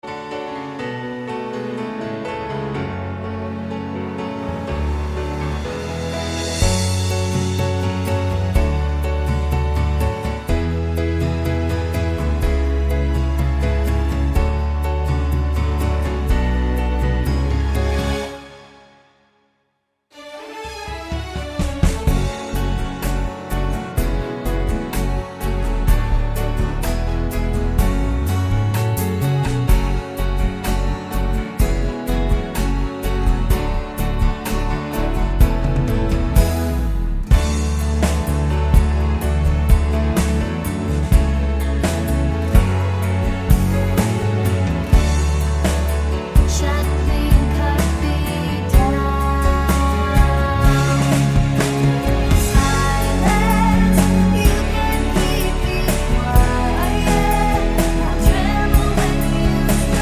no Backing Vocals Soundtracks 3:26 Buy £1.50